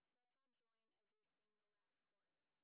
sp30_street_snr10.wav